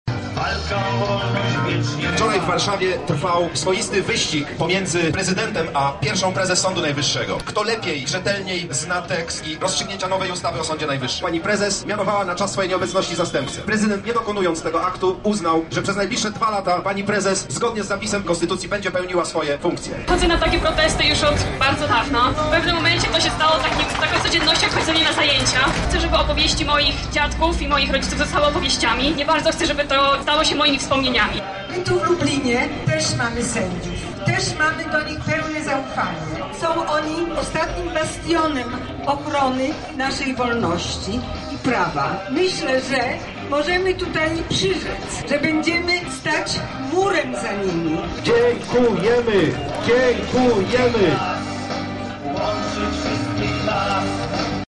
W proteście brało udział około 200 osób.